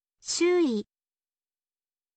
shuui